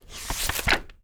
TURN PAGE4-S.WAV